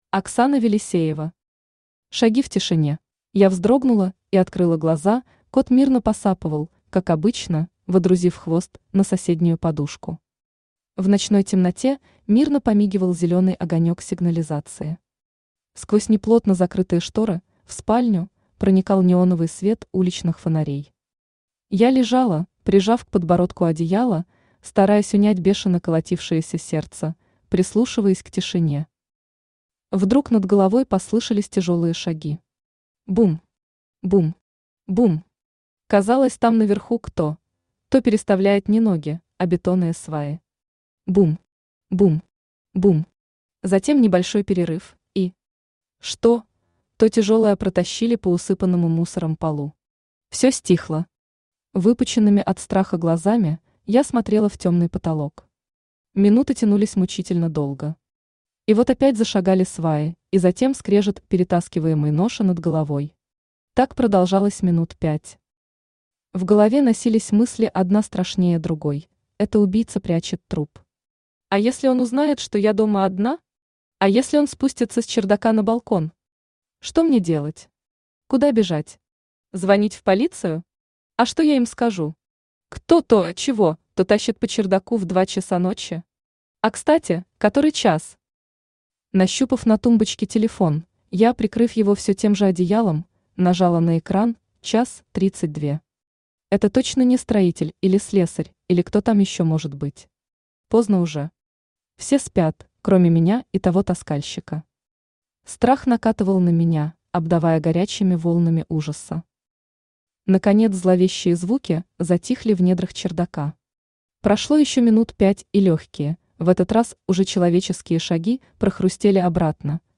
Aудиокнига Шаги в тишине Автор Оксана Велисеева Читает аудиокнигу Авточтец ЛитРес.